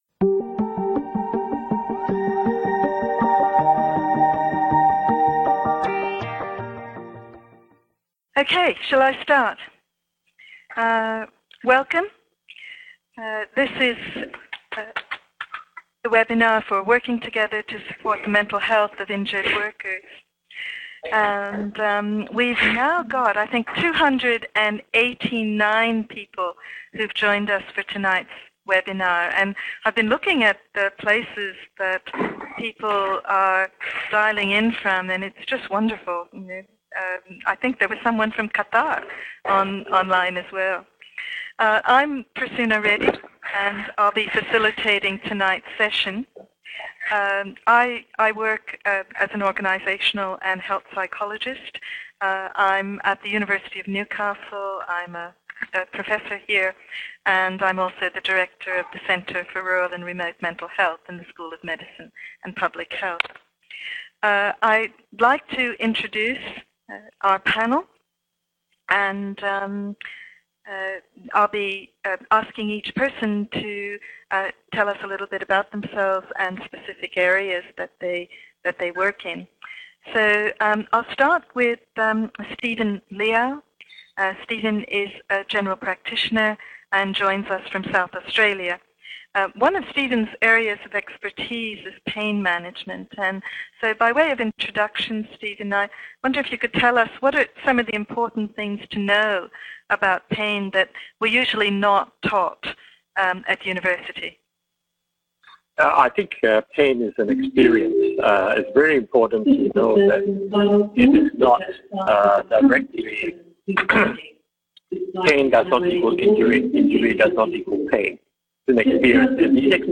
Join our interdisciplinary panel as we discuss how to best support the mental health of injured workers during their recovery and return to the workforce. The discussion centres on Matt, who has suffered an assault in the workplace.